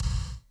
KIK TR 9.wav